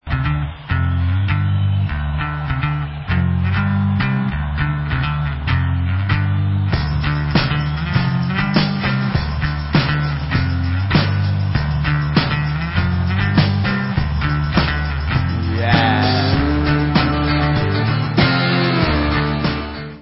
Live At Fox Theatre, 2001
sledovat novinky v oddělení Southern (jižanský) rock